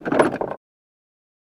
Cessna Pedal Down